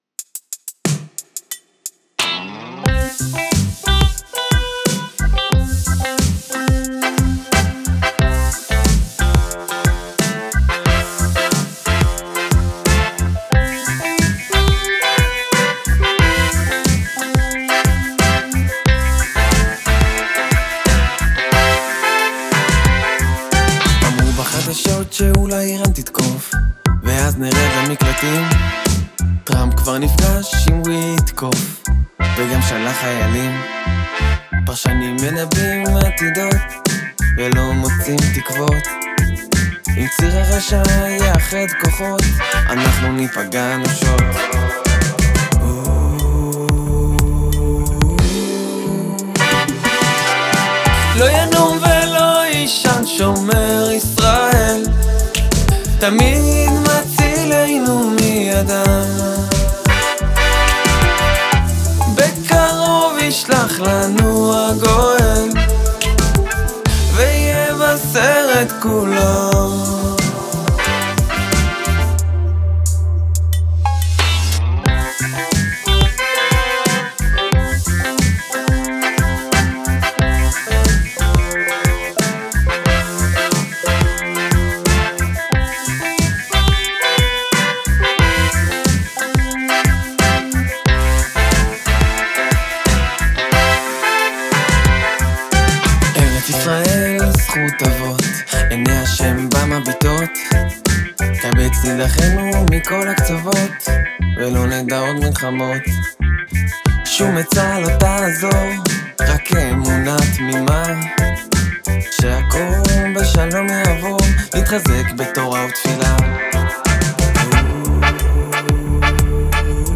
בפזמון ציפיתי לקצת יותר מילוי (פד, וכדומה) , הדגימות של הבראס יכולות להיות טובות יותר